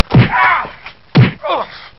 دانلود آهنگ مشت زدن 3 از افکت صوتی انسان و موجودات زنده
جلوه های صوتی
دانلود صدای مشت زدن 3 از ساعد نیوز با لینک مستقیم و کیفیت بالا